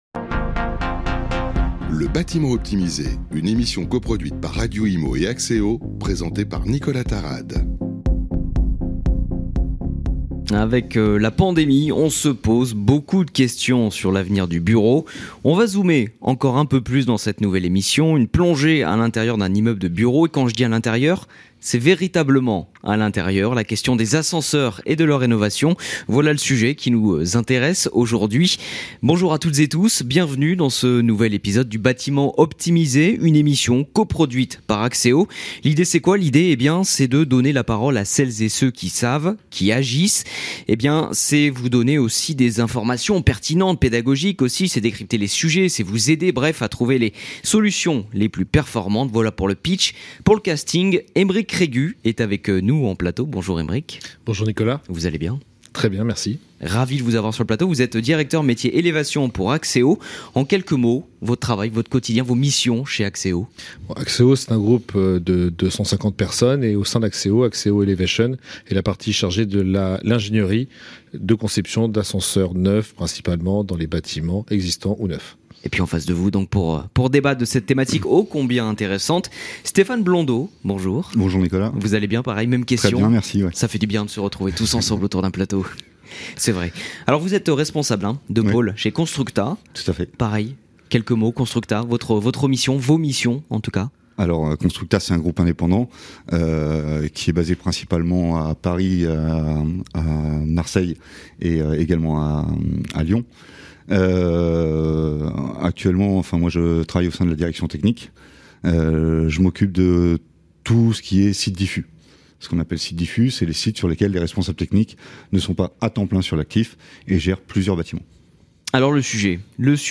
Retrouvez de nombreux invités autour du plateau radio installé au coeur de l'évènement à la Porte de Versailles à Paris.